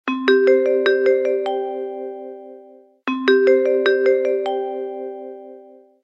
samsung-galaxy-chimes_24559.mp3